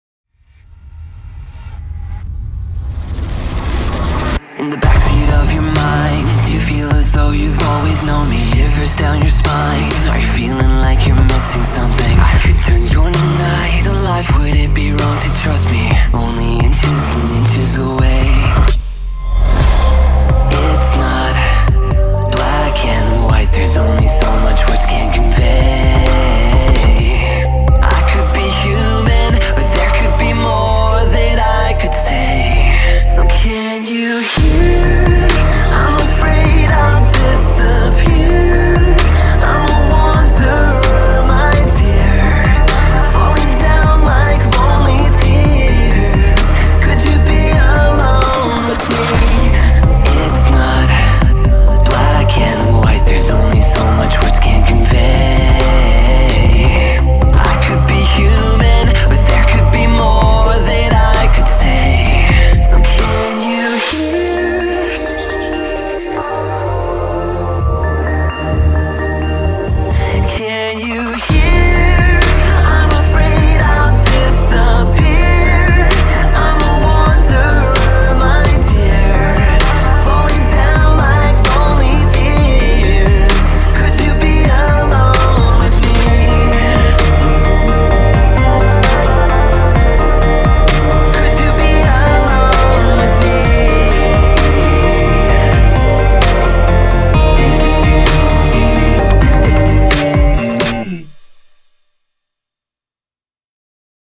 BPM65
MP3 QualityMusic Cut